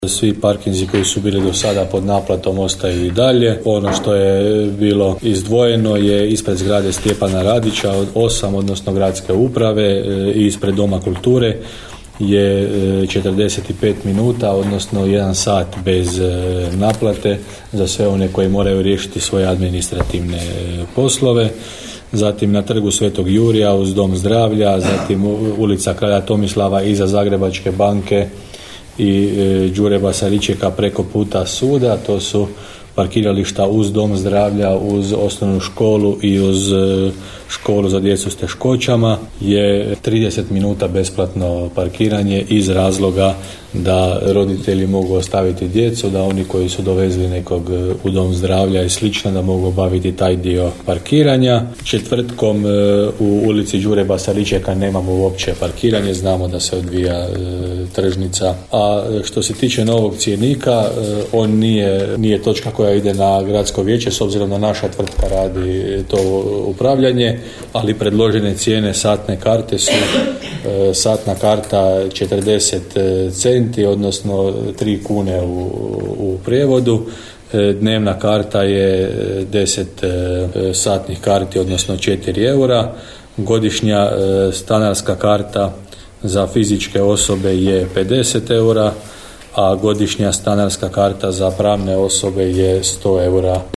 Spomenimo i da su u gotovo dvosatnoj sjednici Gradskog vijeća predsjednik Željko Lacković i gradonačelnik Hrvoje Janči strpljivo odgovarali i na pitanja oporbenih vijećnika Fucaka i Gašparića.